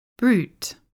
因此，我们录制了一些关键葡萄酒术语并创建了这个读音指南。
我们的宗旨是以较为被世界广泛人接受的读音来作参考。